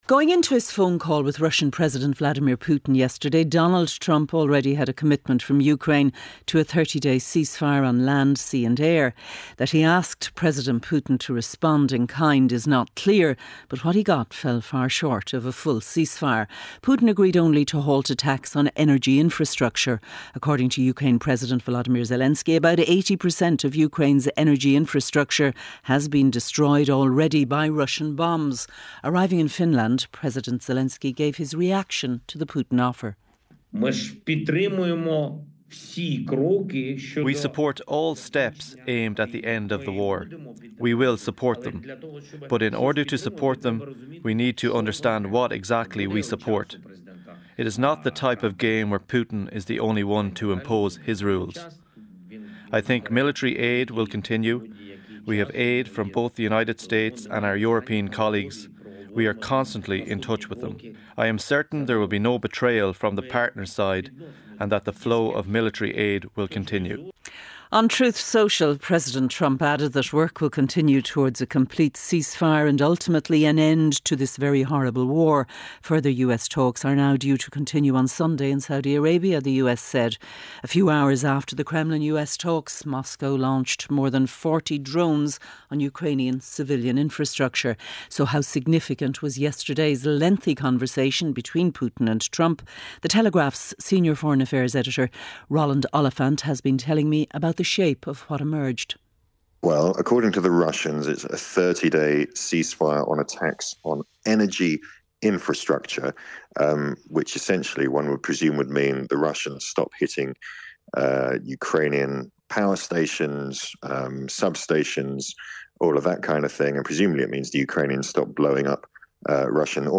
7:50am Business News - 19.03.2025